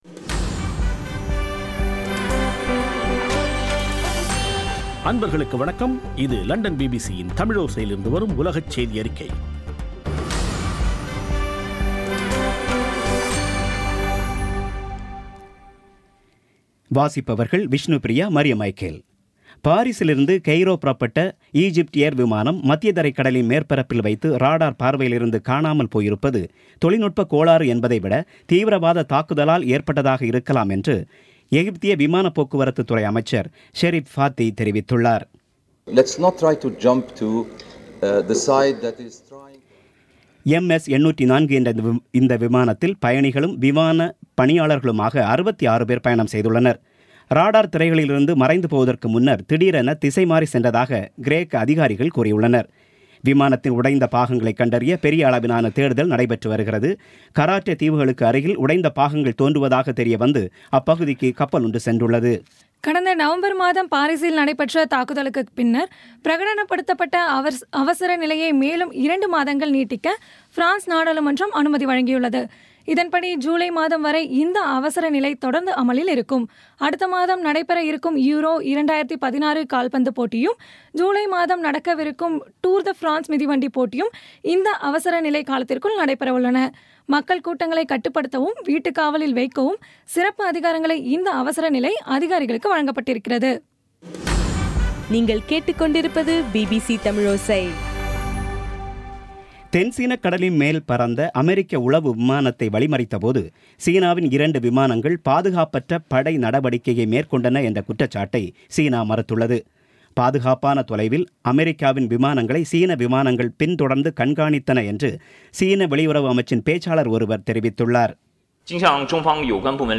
இன்றைய (மே 19ம் தேதி ) பிபிசி தமிழோசை செய்தியறிக்கை